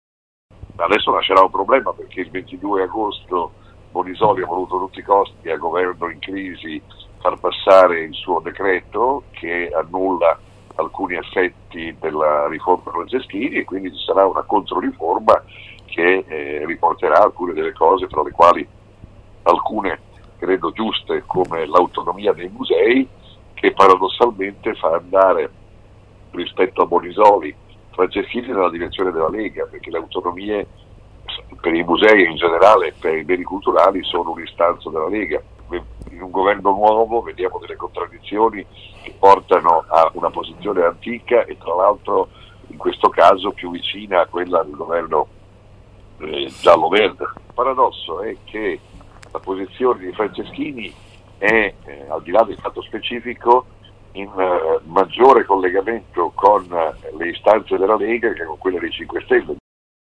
Il critico ai microfoni di LumsaNews
Vittorio-Sgarbi-Intervista.mp3